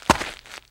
STEPS Gravel, Walk 03.wav